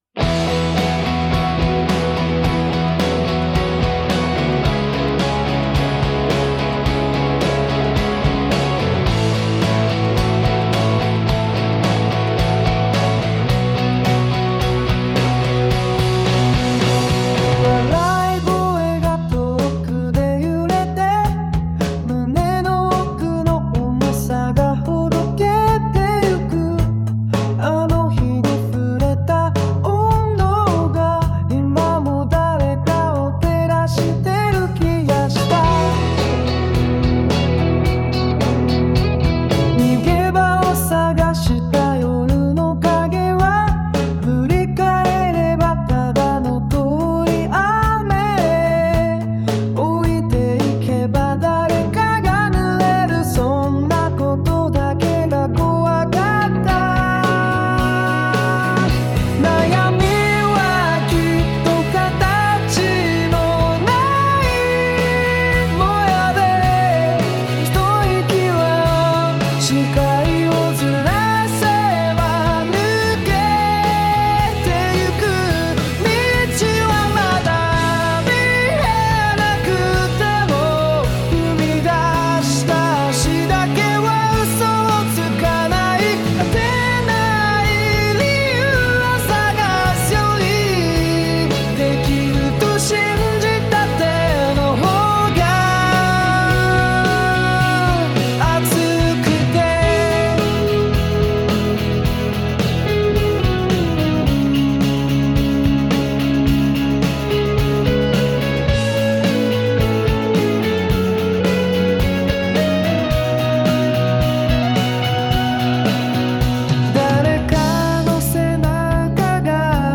ChatGPTと音楽を生成するAIを使って音楽作り始めましたｗ